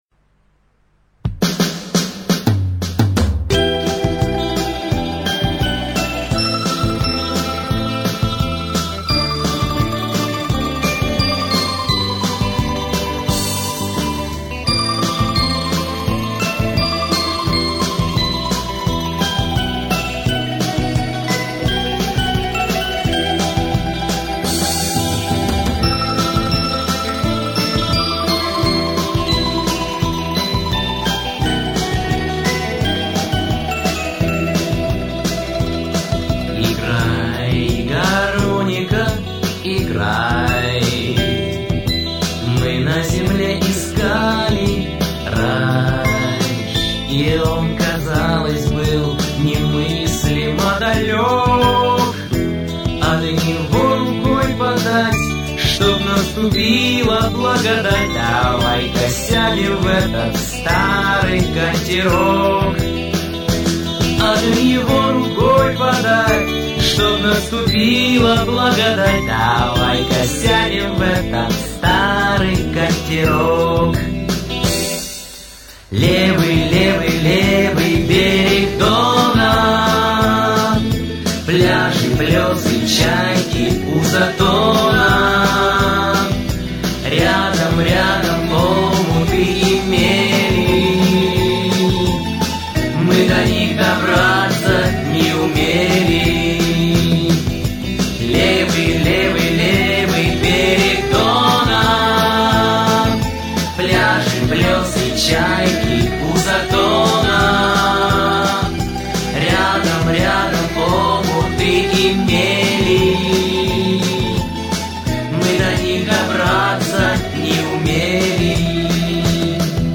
Я надеюсь что и вы те кто по разным причинам еще не спит получит тоже удовольствие от этой мелодичной песни.